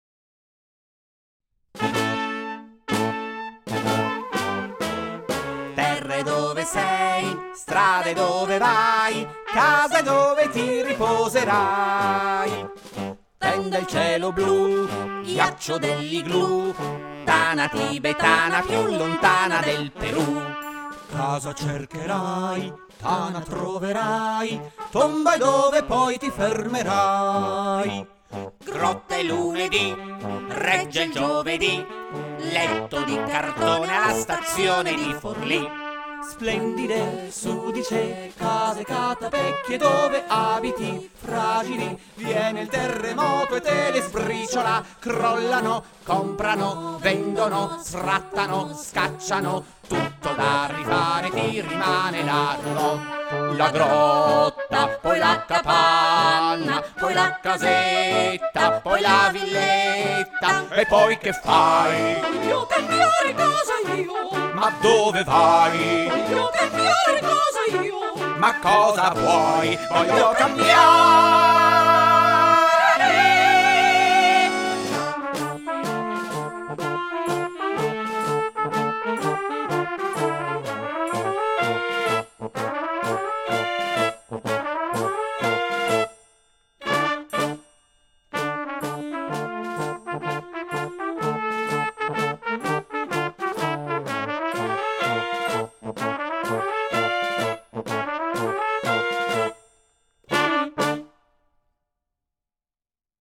Versi per la musica di scena composta da Antonello Murgia (
qui il brano MP3 cantato dai due attori)